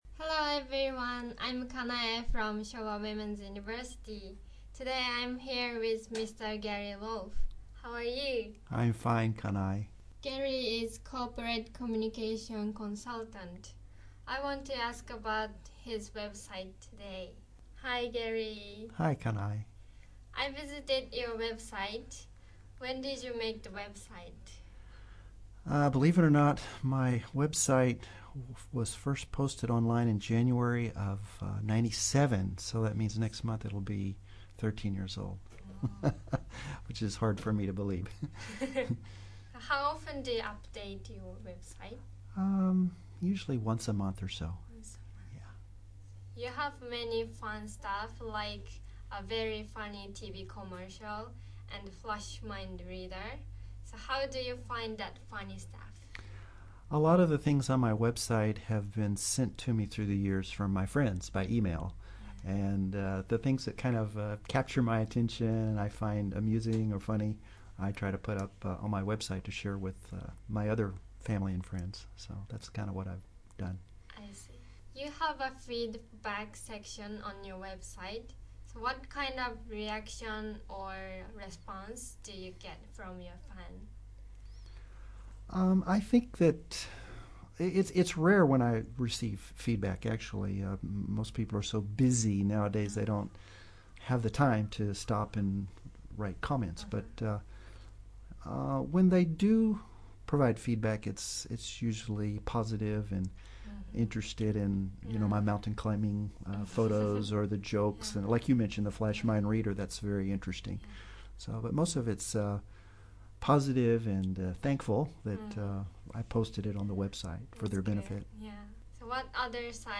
Oct. 31, 2012 - FM Setagaya 83.4 MHz radio interviews
The radio show was called "What's Up Setagaya?" and broadcast on FM Setagaya 83.4 MHz.